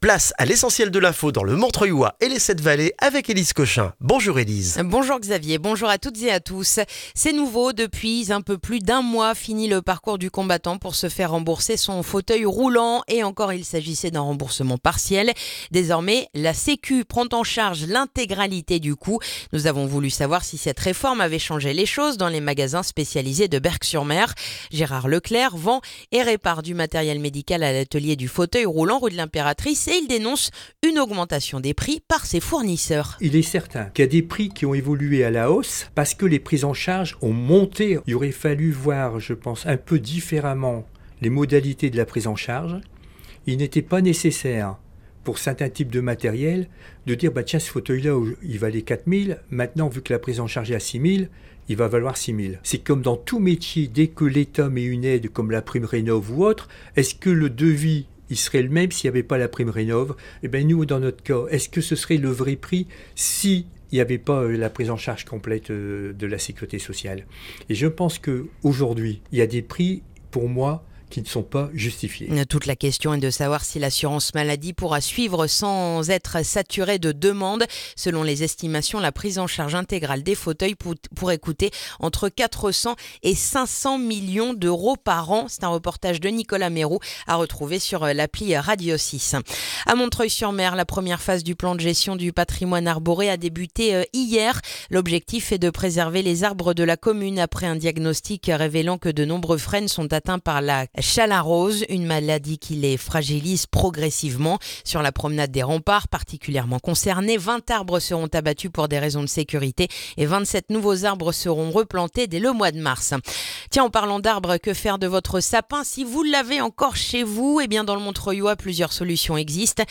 Le journal du mardi 13 janvier dans le montreuillois